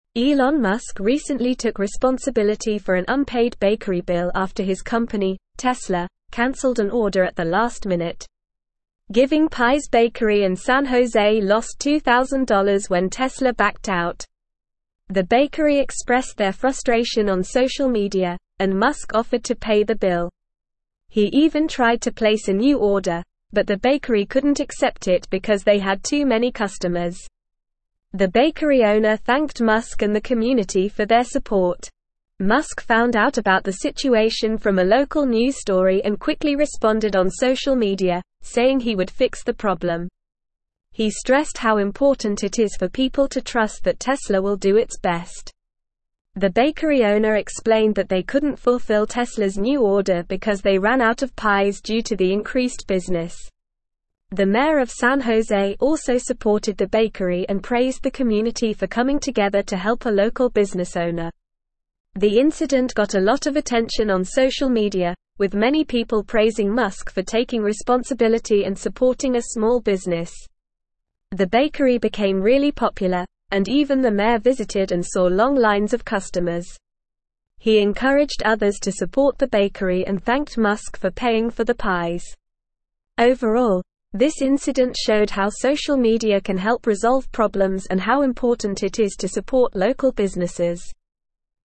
Normal
English-Newsroom-Upper-Intermediate-NORMAL-Reading-Elon-Musk-Saves-Bakery-Receives-Overwhelming-Community-Support.mp3